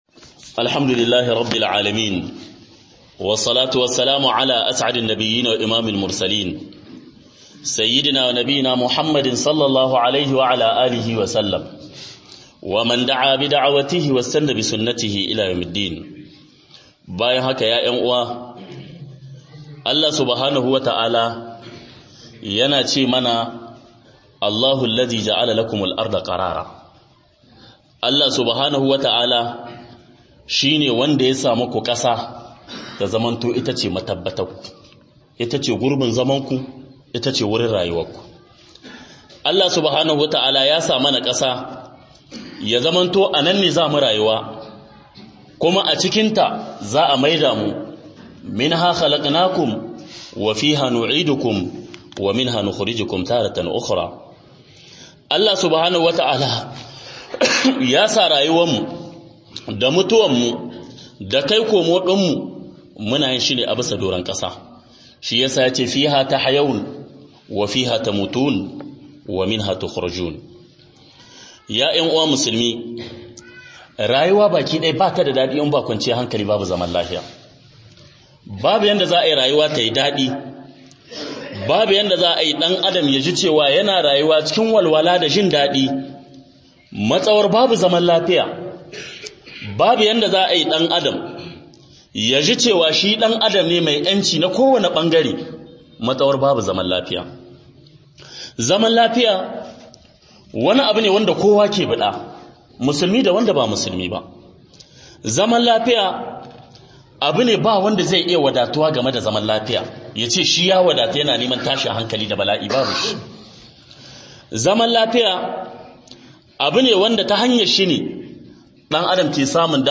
MUHADARA